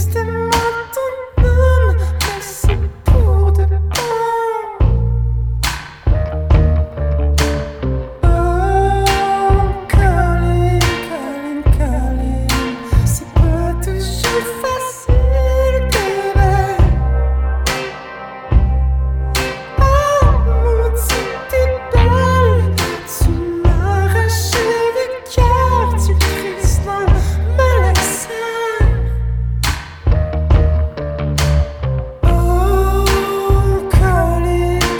Жанр: Поп музыка / Альтернатива